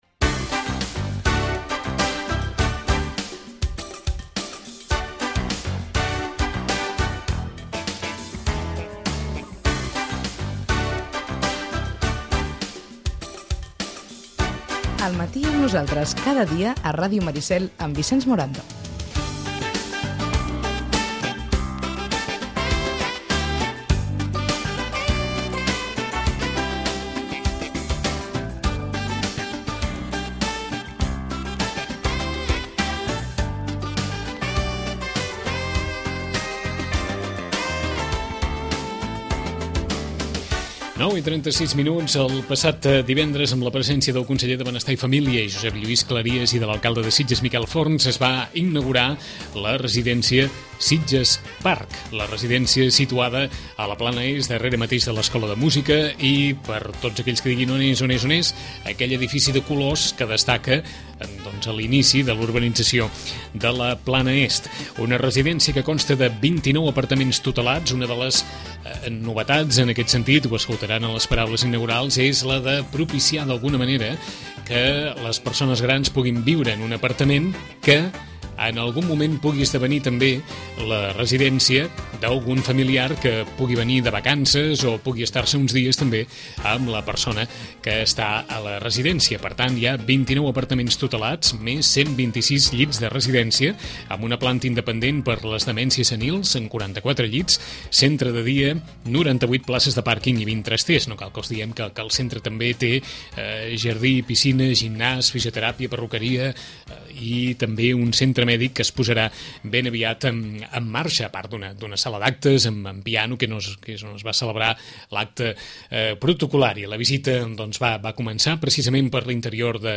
Us oferim l’acte d’inauguració de la nova residència geriàtrica Sitges Park.